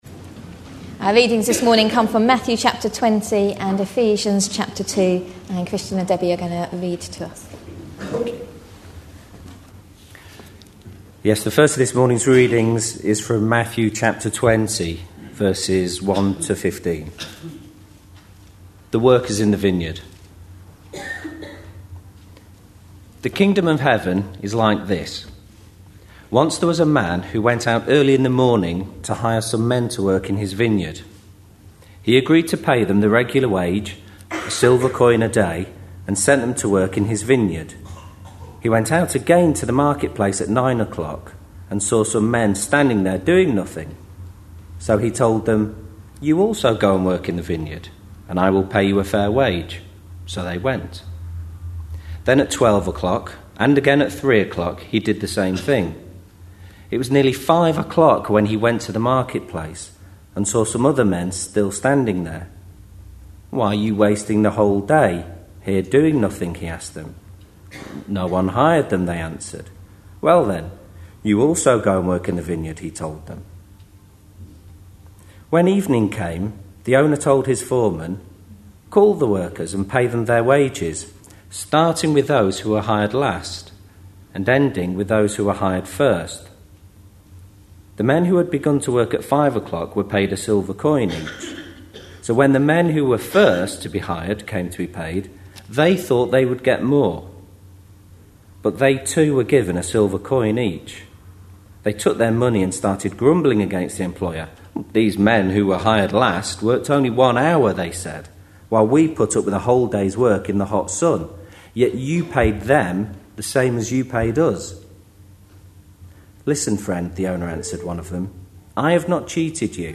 A sermon preached on 24th October, 2010, as part of our Parables of Matthew series.